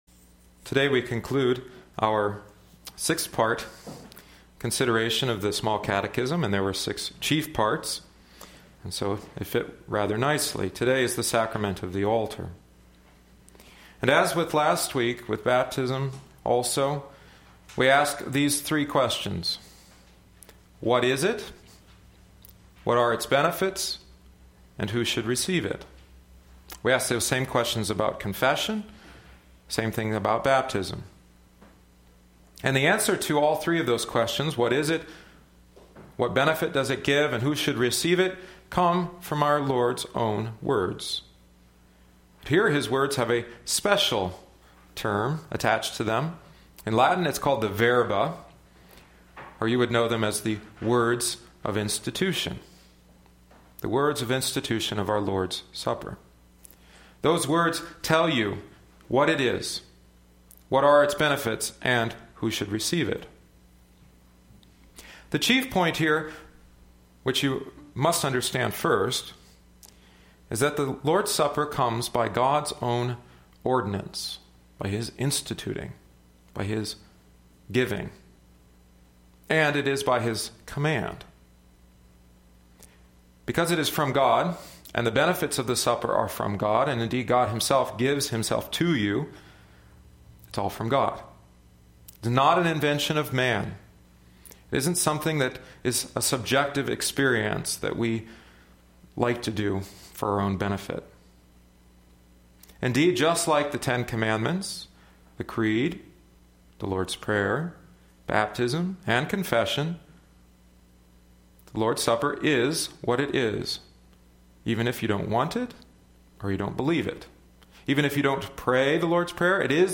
Lenten Catechesis VI 2012 – Sacrament of the Altar